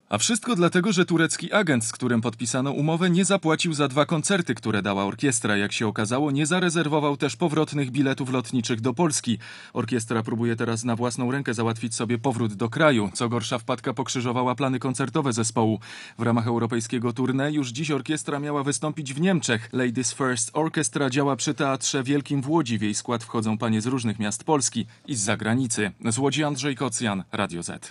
O kłopotach orkiestry opowiada reporter Radia Zet